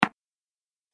دانلود آهنگ تصادف 37 از افکت صوتی حمل و نقل
جلوه های صوتی
دانلود صدای تصادف 37 از ساعد نیوز با لینک مستقیم و کیفیت بالا